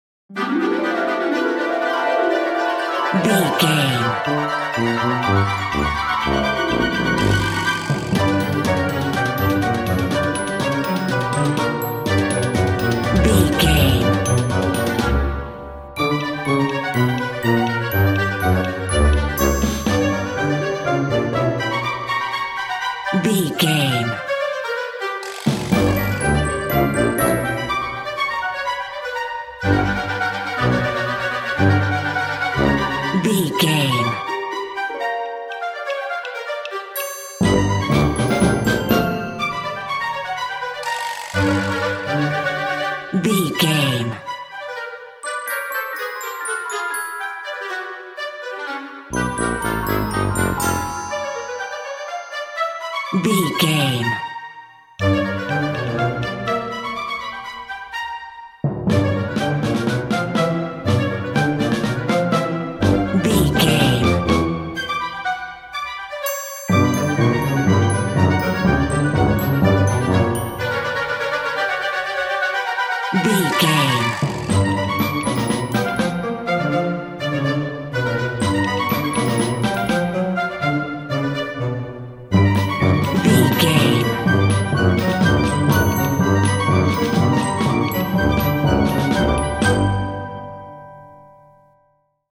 A fast/zany orchestral piece with hints of comical mischief.
Mixolydian
Fast
energetic
lively
playful
flute
oboe
strings
orchestra
harp